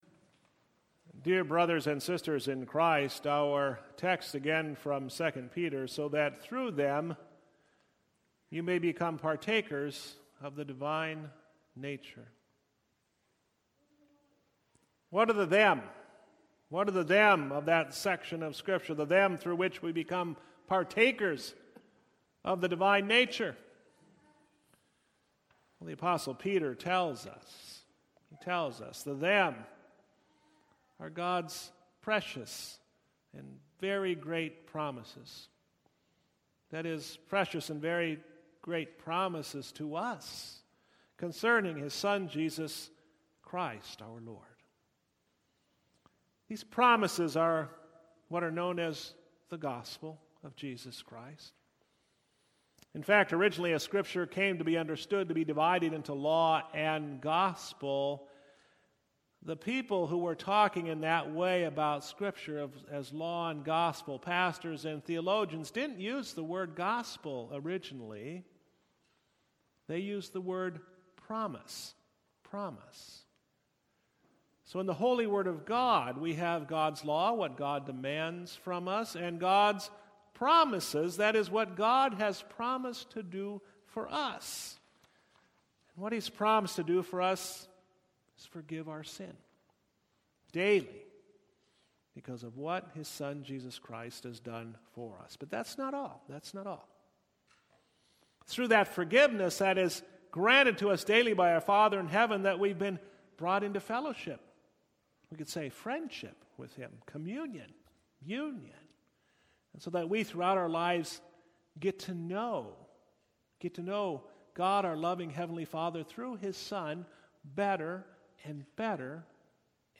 Ash-Wednesday-2022.mp3